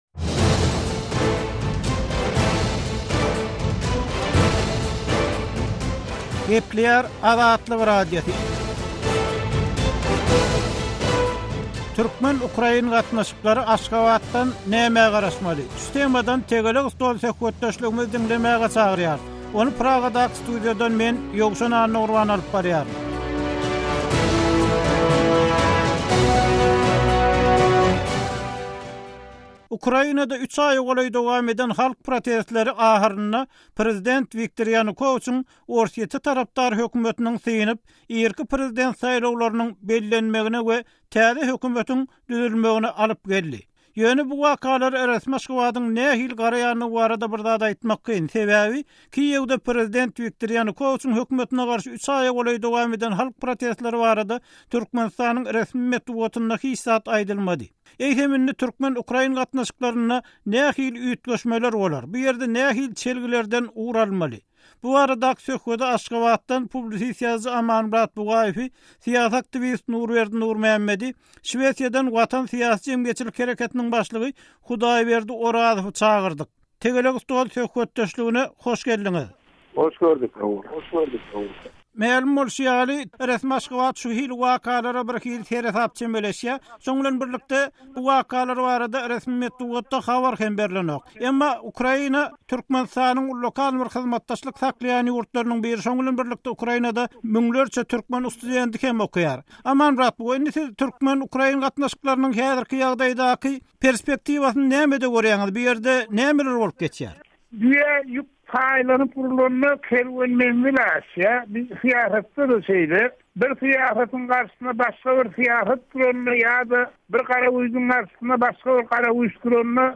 Eýsem Ýewromaýdan rewolýusiýasyndan soň türkmen-ukrain gatnaşyklary nähili bolar? Bu barada ýerli synçylaryň pikir-garaýyşlaryny bilmek isleseňiz, "Tegelek stol" söhbetdeşligimizi diňläň.